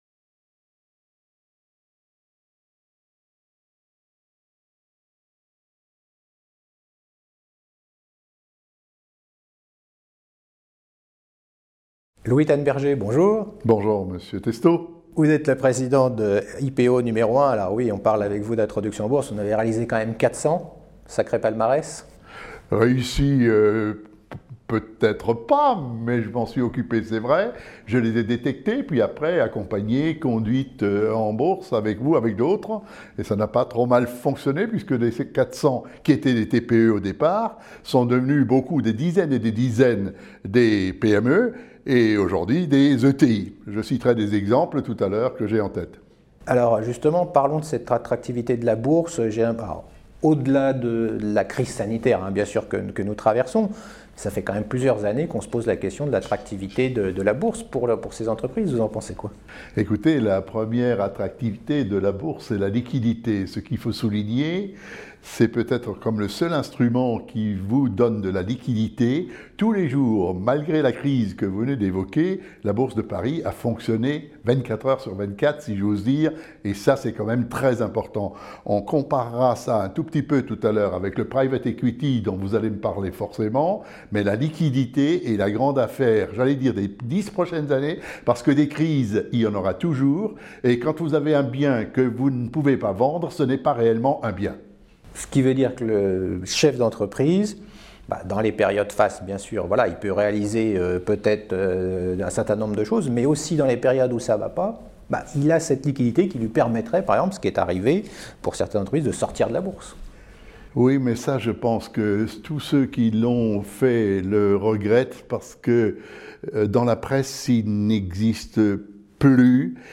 La Bourse et la Vie porte bien son nom aujourd’hui dans cette interview.